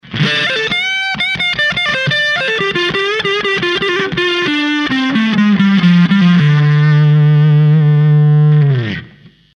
1N60Aは太い感じがします。
かといって高域が鈍くなるわけでもないですし